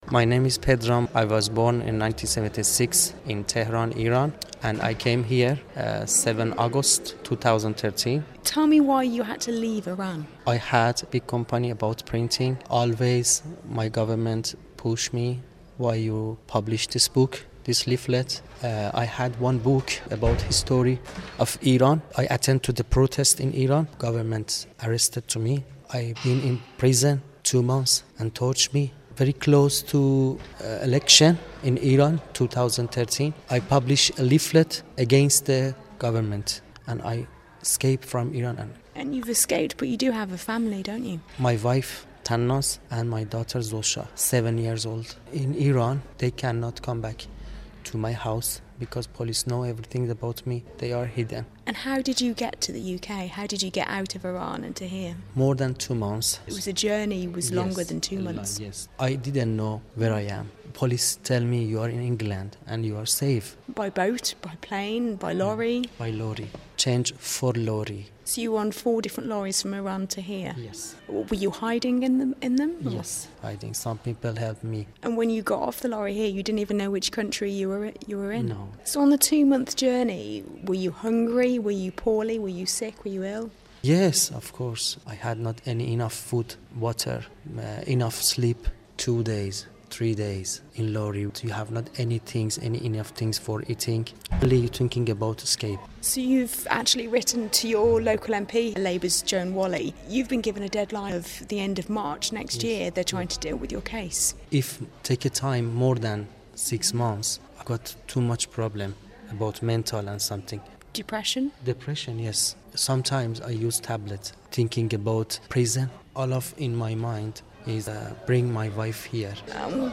(Broadcast on BBC Radio Stoke, September 2014)